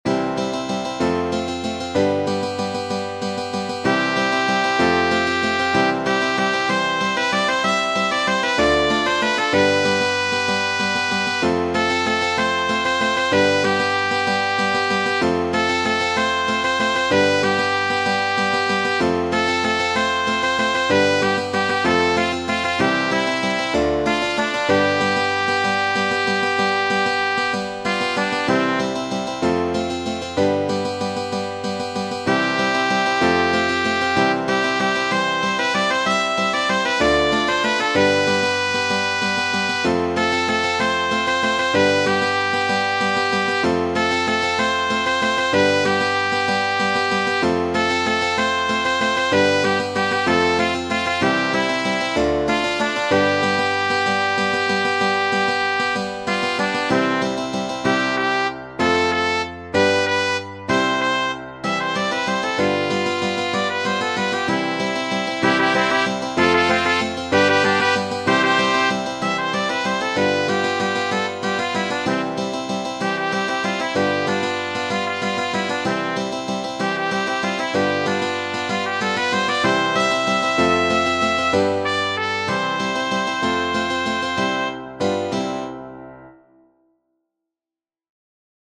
Genere: Folk
"Alma Llanera" è una famosa canzone joropo composta dal musicista venezuelano Pedro Elías Gutiérrez su parole di Rafael Bolívar Coronado.